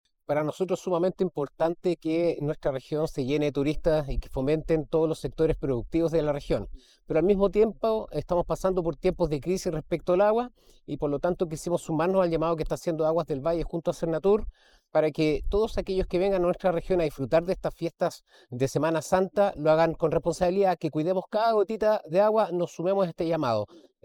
AUDIO : Gobernador Regional, Cristóbal Juliá